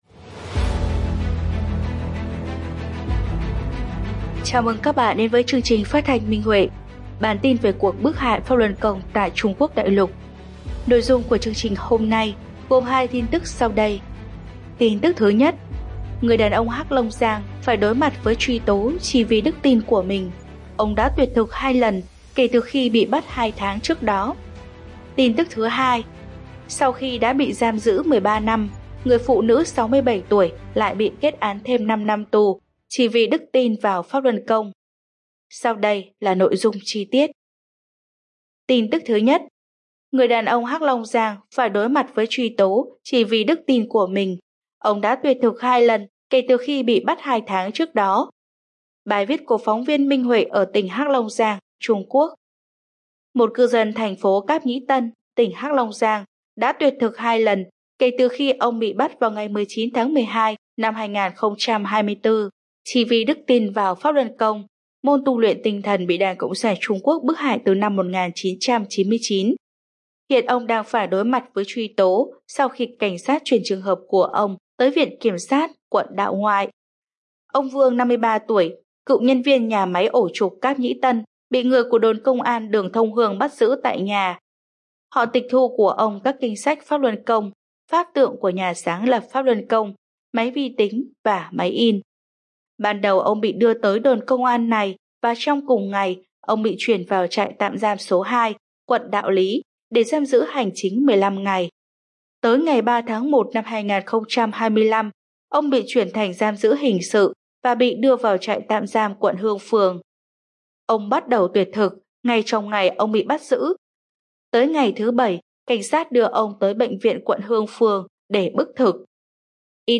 Chương trình phát thanh số 184: Tin tức Pháp Luân Đại Pháp tại Đại Lục – Ngày 25/2/2025